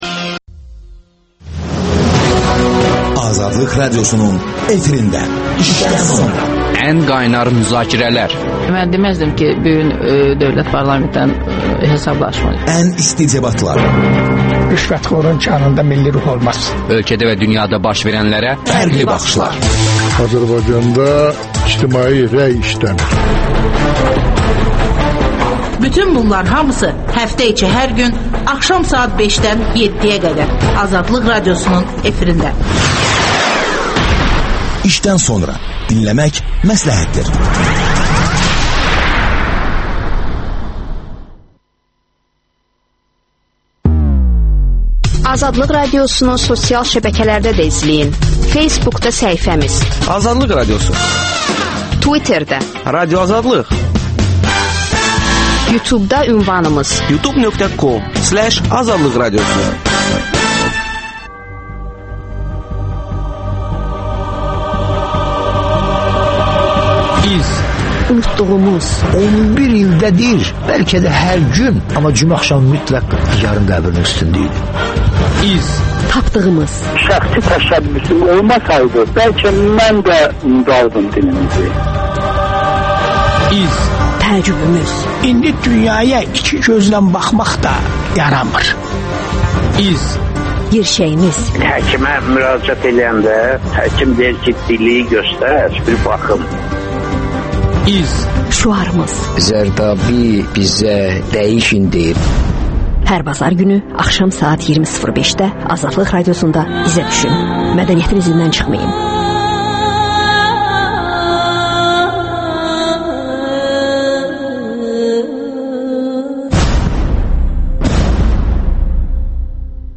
İki jurnalist və bir tərəf.